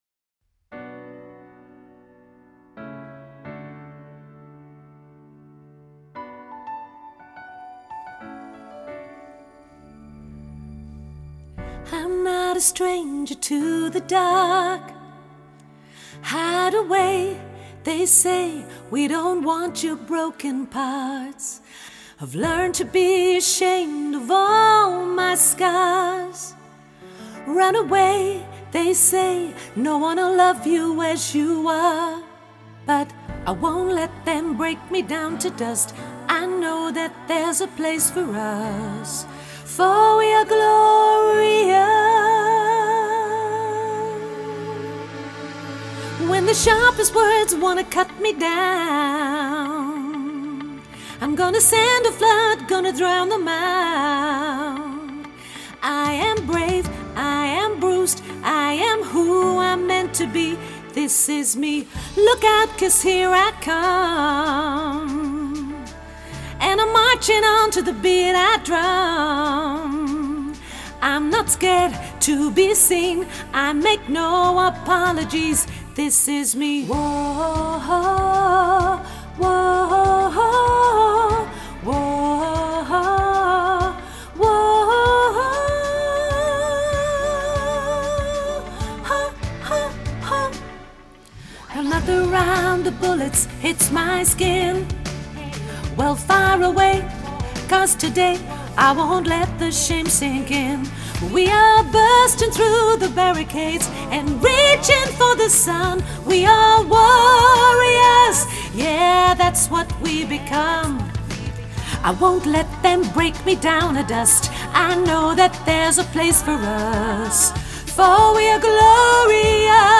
mezzo sopraan